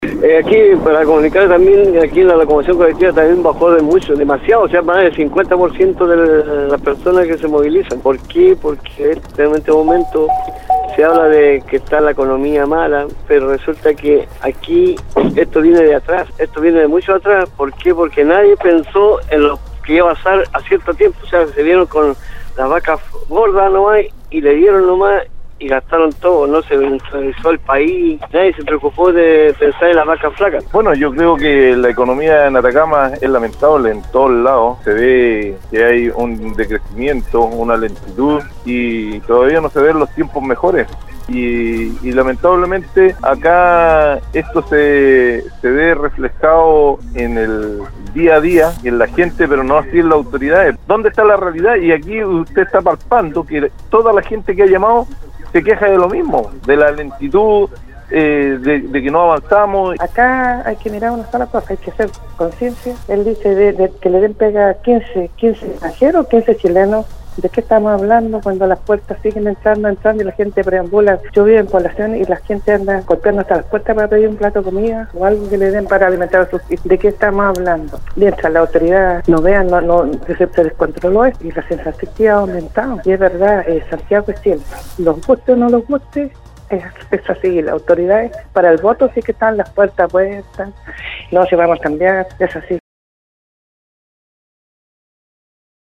La mañana de este martes, auditores de Nostálgica participaron en el foro del programa Al Día donde se refirieron a la visión que tienen sobre la situación actual de la economía en la región de Atacama y cuáles serían para ellos, las principales causas o consecuencias de que muchas personas no cuenten con un trabajo estable que les permita cubrir sus necesidades básicas.